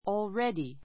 already 中 A1 ɔːlrédi オー る レ ディ 副詞 （ ⦣ 比較変化なし） 既 すで に , もう It's already dark.